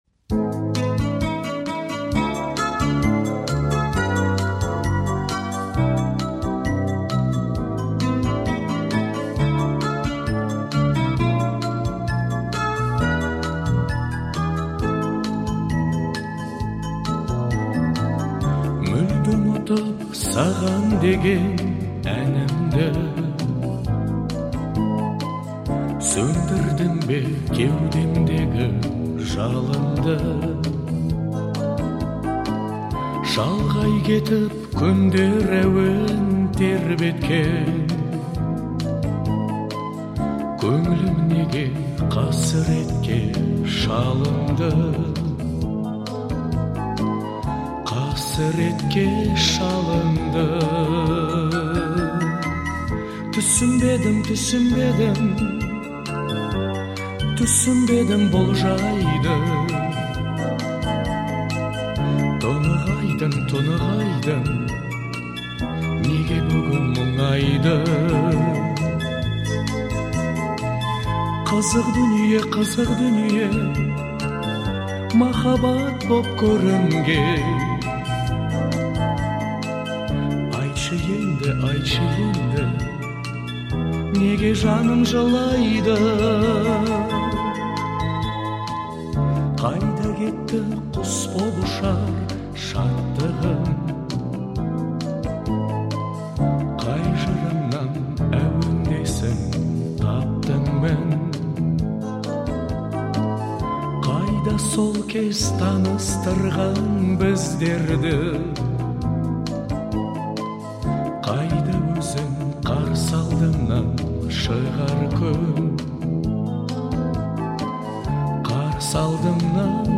это трек в жанре казахского поп-фолка